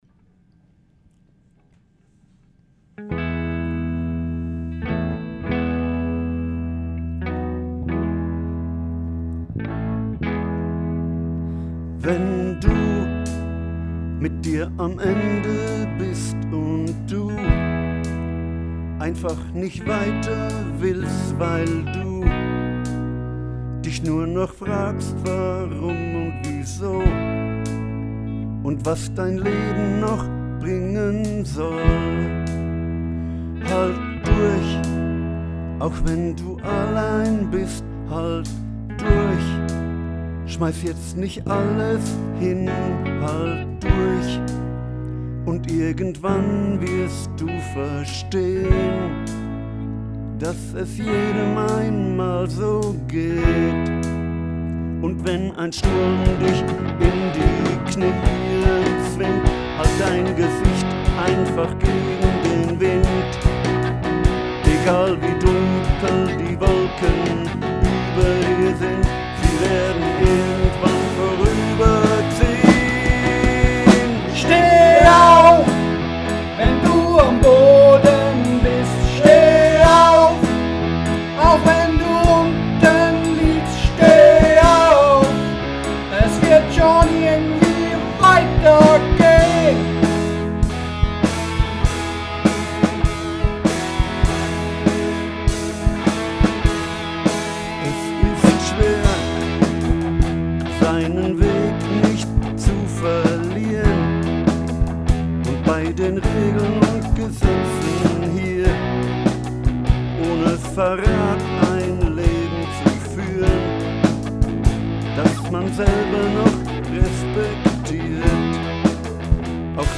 Jetzt gehen wir unter die Punker.